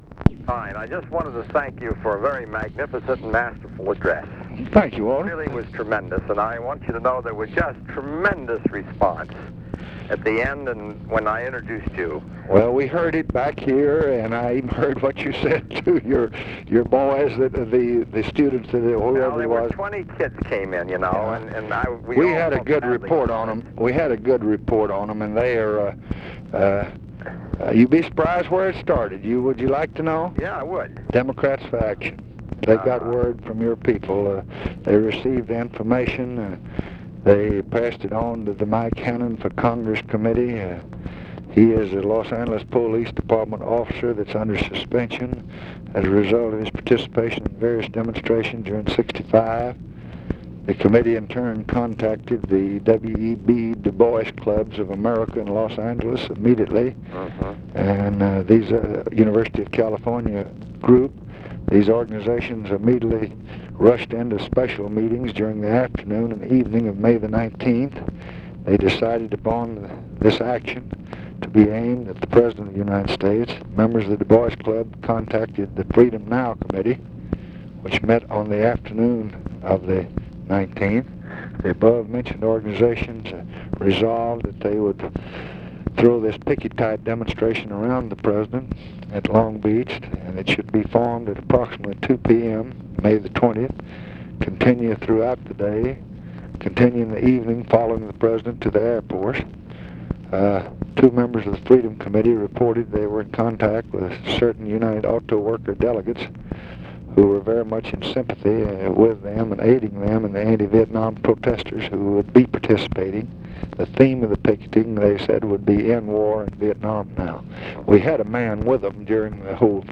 Conversation with WALTER REUTHER, May 21, 1966
Secret White House Tapes